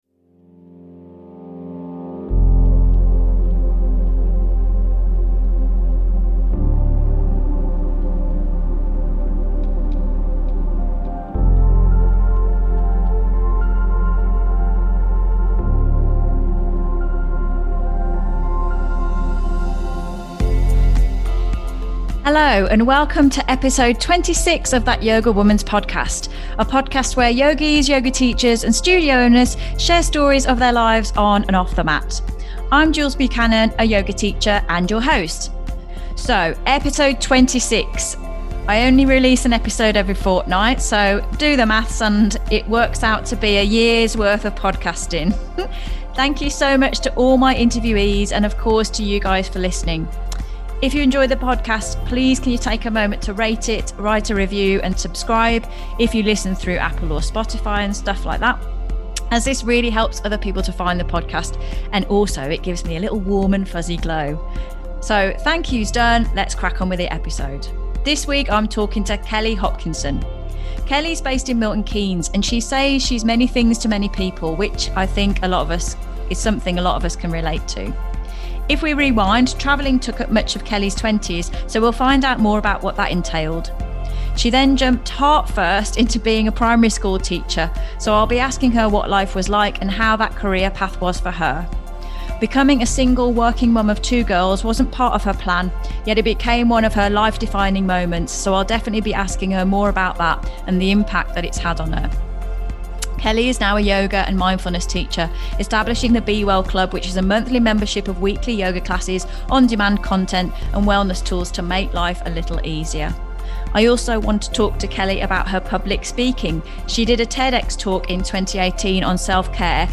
A podcast where yogis, yoga teachers and studio owners share stories of their lives on and off the mat.